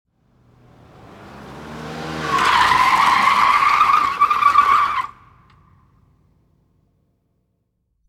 Download Vehicle sound effect for free.
Vehicle